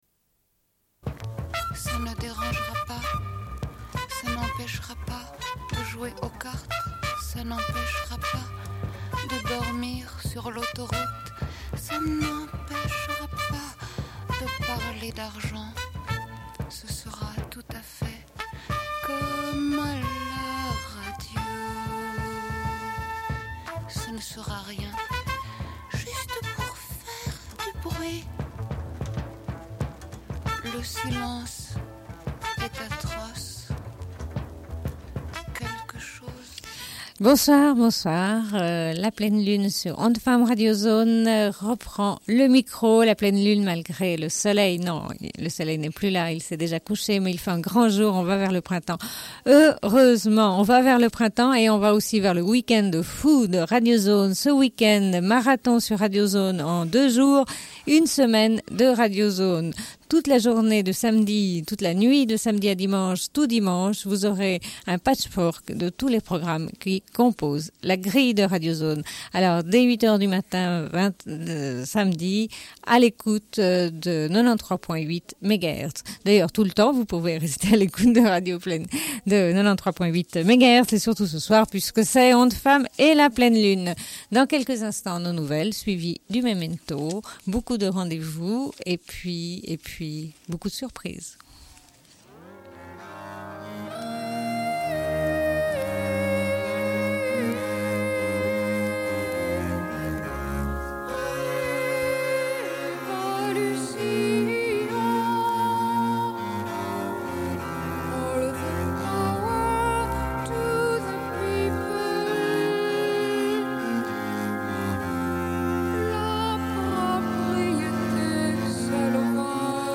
Bulletin d'information de Radio Pleine Lune.